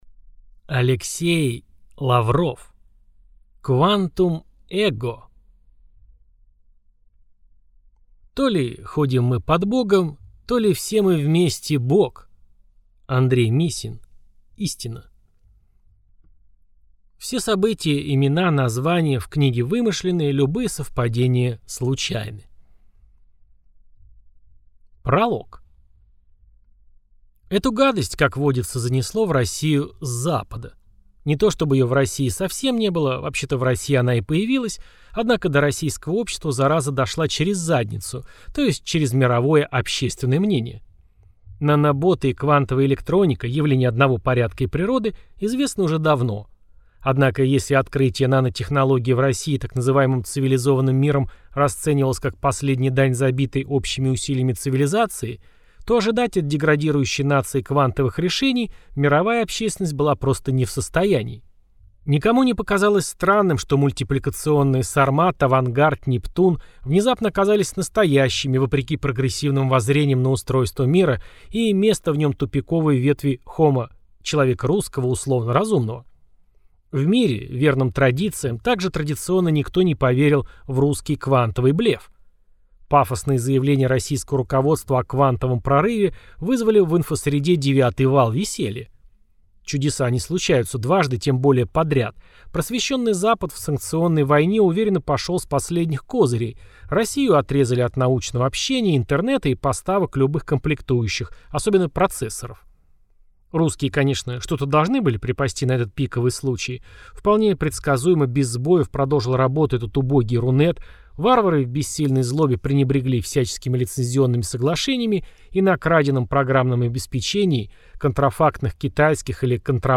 Аудиокнига Квантум Эго | Библиотека аудиокниг
Aудиокнига Квантум Эго